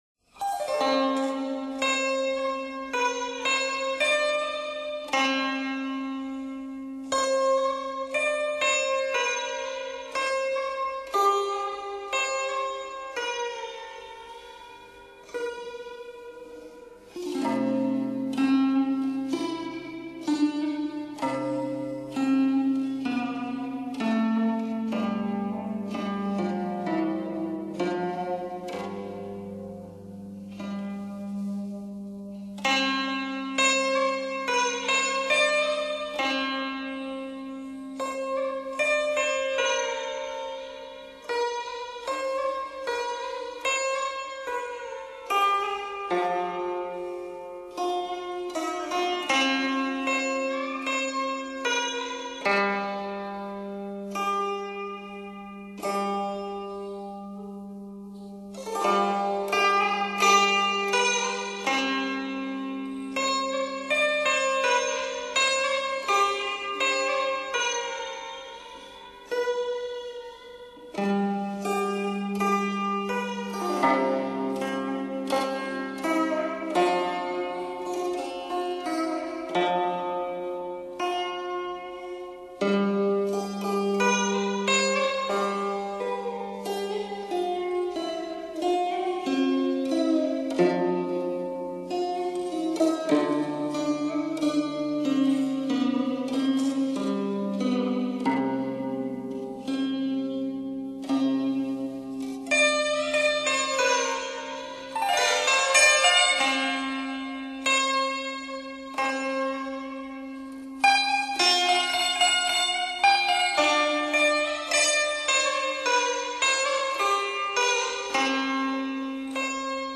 广东潮州民间筝曲